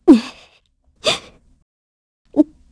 Hilda-Vox_Sad_kr.wav